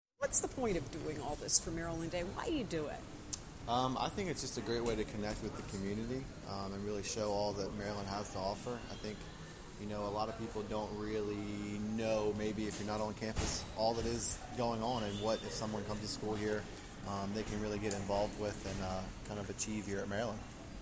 A volunteer tells what MD day means